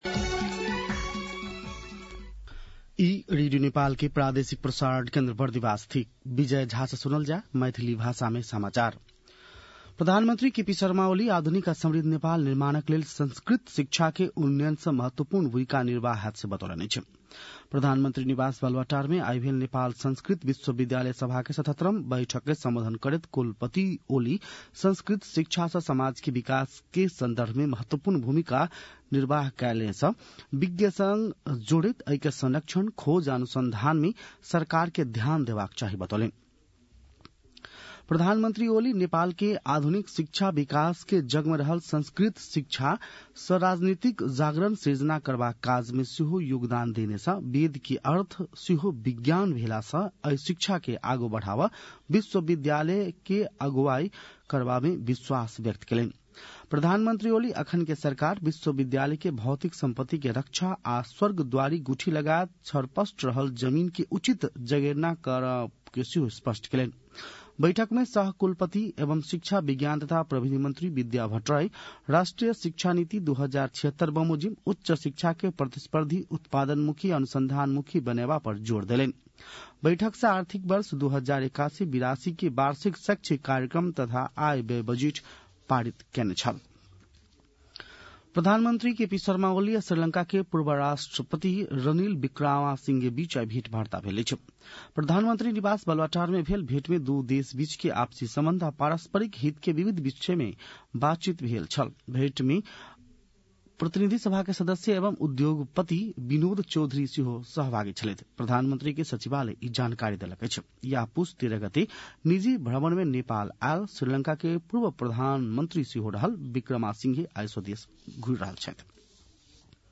मैथिली भाषामा समाचार : १९ पुष , २०८१
Maithali-news-9-18.mp3